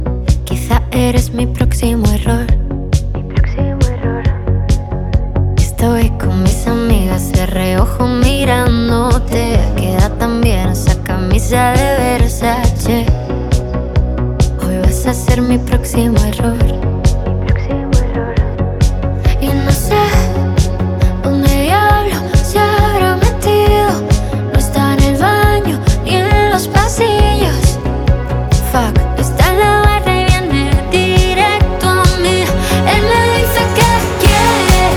Pop Latino